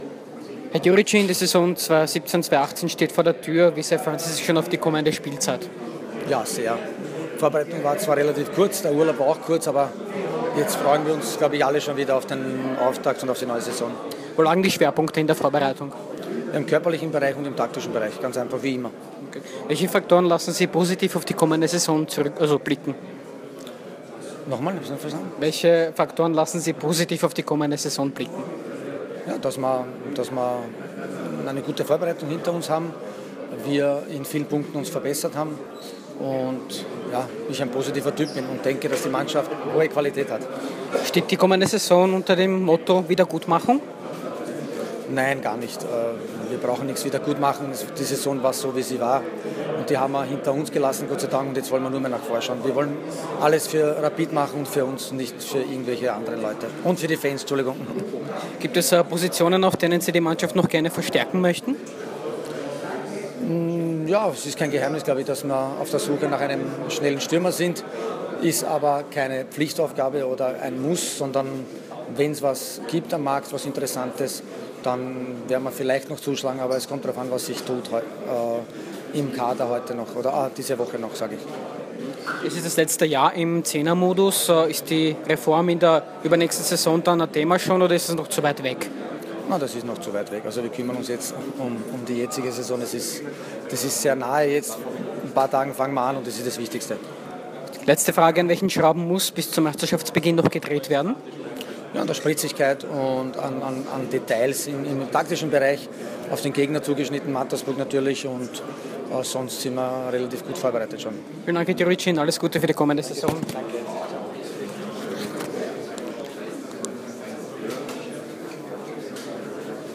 Rapid Wien-Trainer Goran Djuricin im Interview